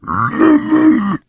Звук он расстроился